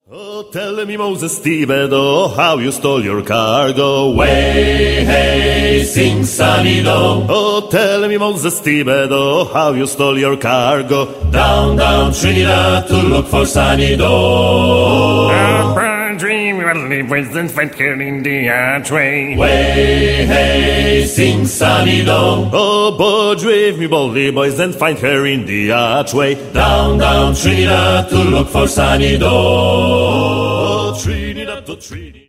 (sł. i mel. trad.)